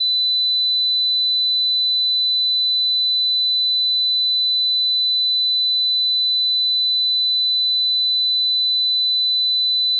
4000HZ.WAV